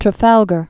(trə-fălgər), Cape